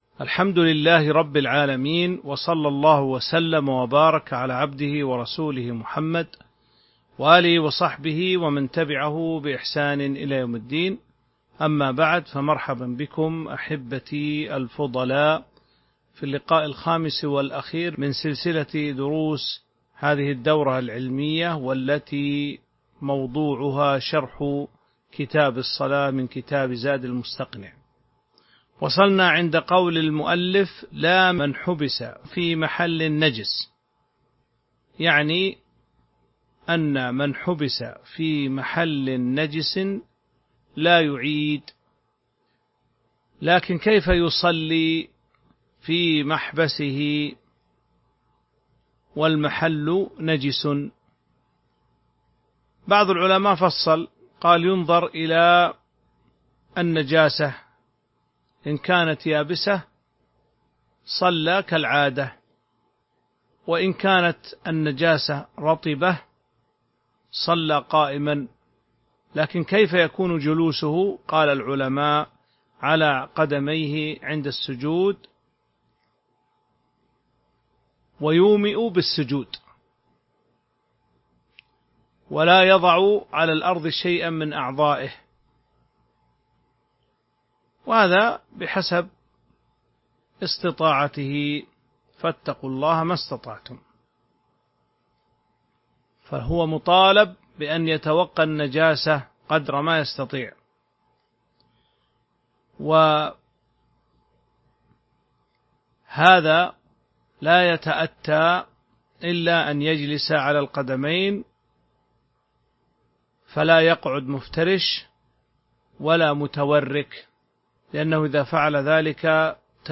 تاريخ النشر ٢٤ ذو الحجة ١٤٤٢ هـ المكان: المسجد النبوي الشيخ